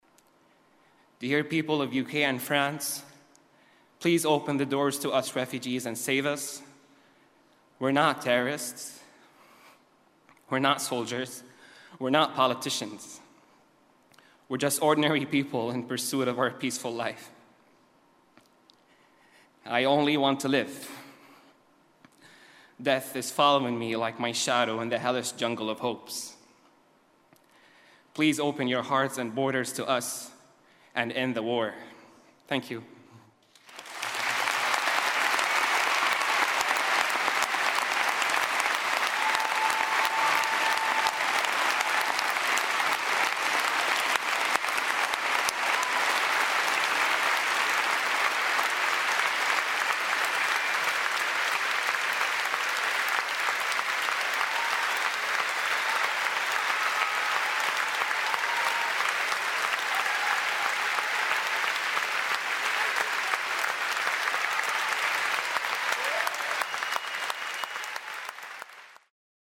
在线英语听力室见信如晤Letters Live第33期:'哈桑'读信:请别将难民拒之门外(3)的听力文件下载,《见信如唔 Letters Live》是英国一档书信朗读节目，旨在向向书信艺术致敬，邀请音乐、影视、文艺界的名人，如卷福、抖森等，现场朗读近一个世纪以来令人难忘的书信。